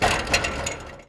metal_gate_fence_impact_04.wav